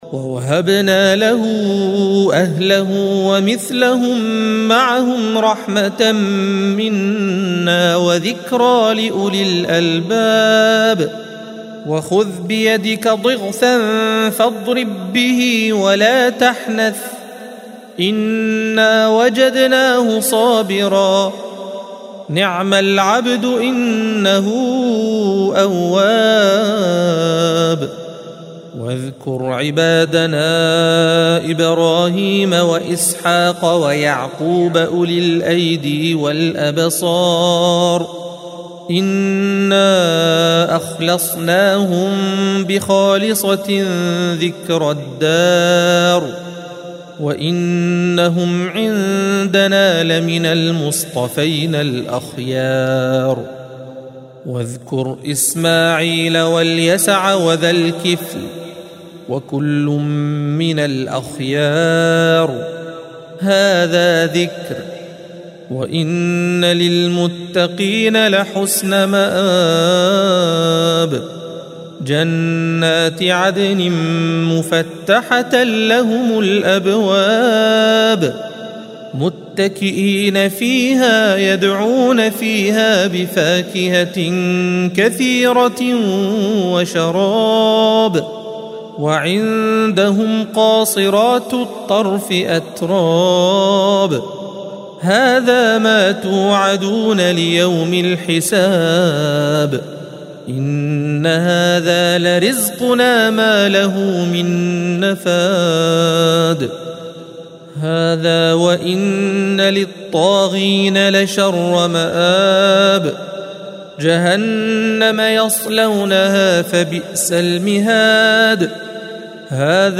الصفحة 456 - القارئ